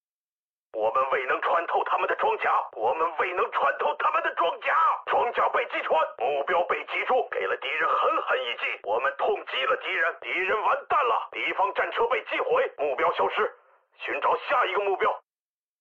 333乘员语音-男-59小钢炮
修改说明 乘员语音-男声
333乘员语音-男.mp3